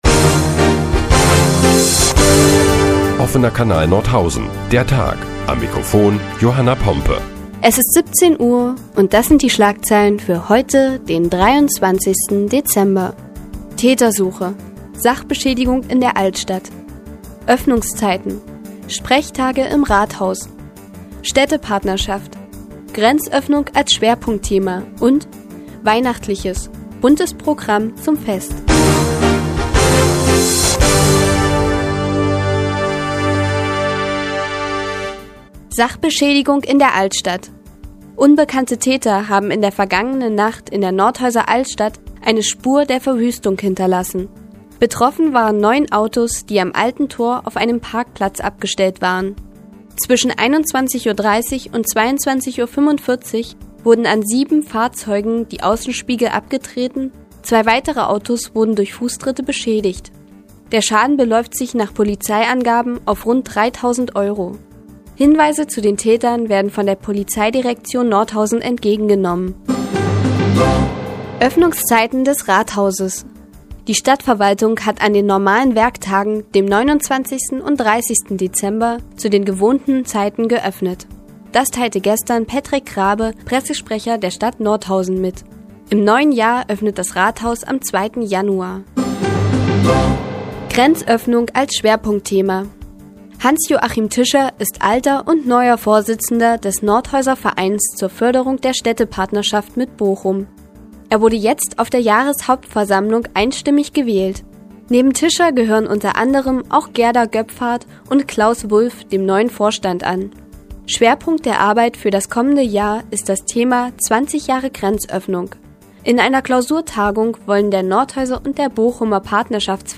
Die tägliche Nachrichtensendung des OKN ist nun auch in der nnz zu hören. Heute geht es unter anderem um Sachbeschädigung in der Altstadt und Sprechtage im Rathaus.